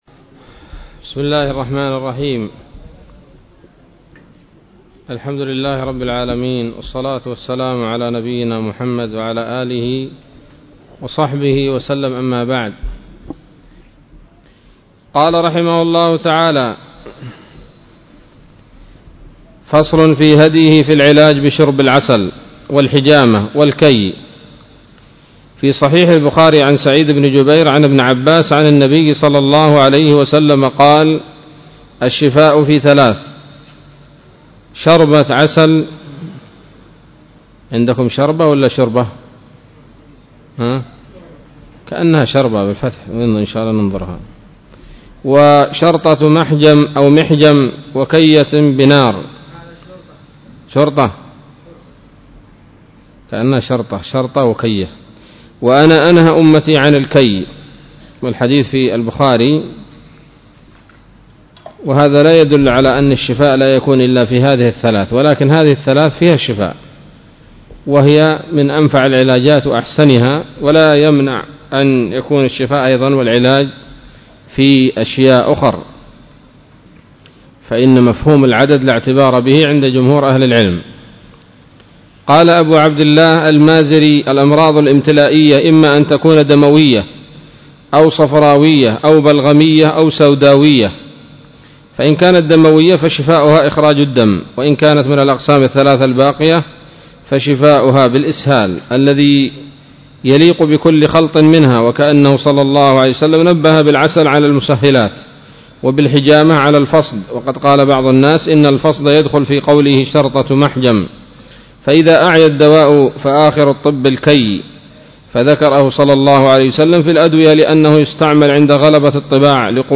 الدرس الرابع عشر من كتاب الطب النبوي لابن القيم